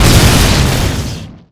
effect_electric_09.wav